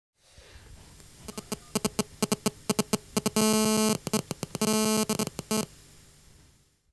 Handystörung
Gerade als Tontechniker hat man oft mit einem Geräusch zu kämpfen das durch ein Handy zu nahe an einem Verstärker produziert werden kann.
handystoerung